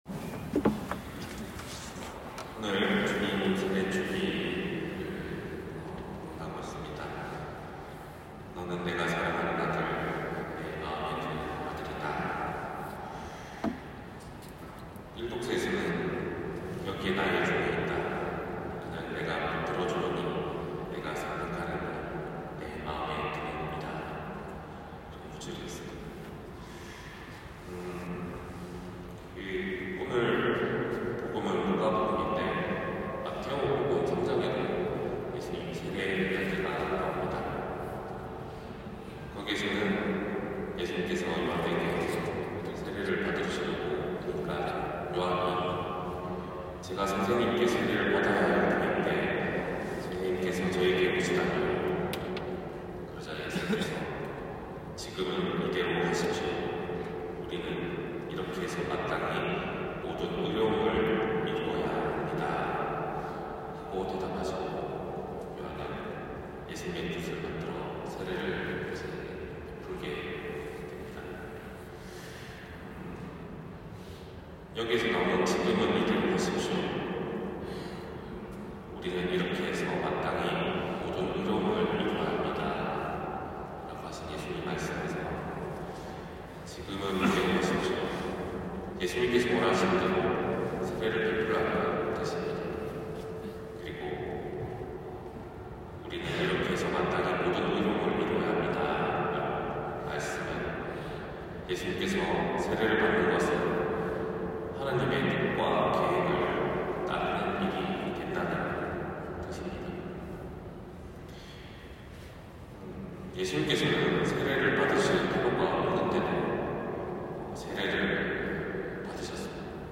250111신부님 강론말씀